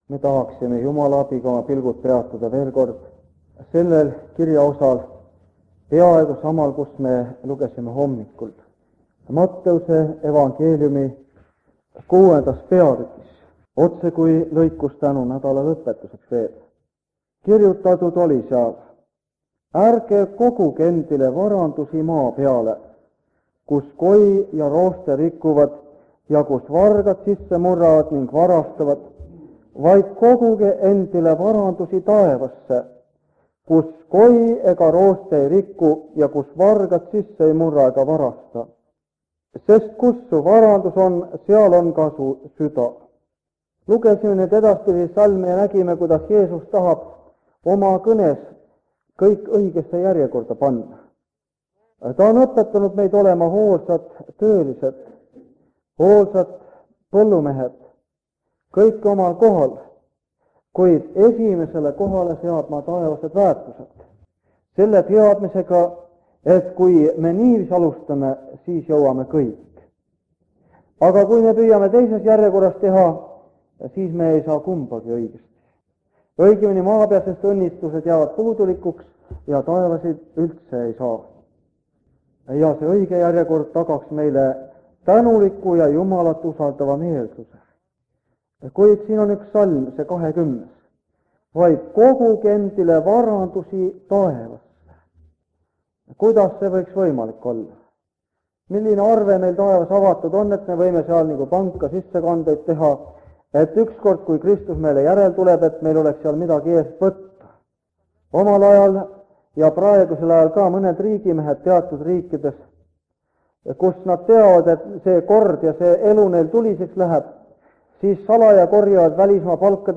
Jutlus vanalt lintmaki lindilt 1977 aasta Kingissepa linnast Saaremaalt.